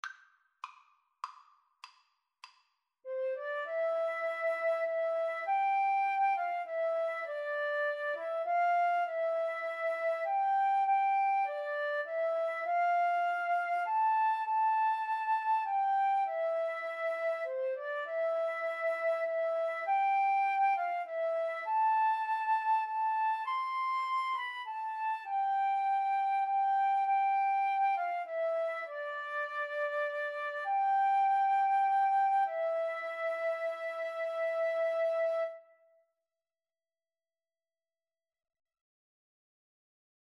6/4 (View more 6/4 Music)
Classical (View more Classical Flute Duet Music)